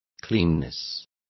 Complete with pronunciation of the translation of cleanness.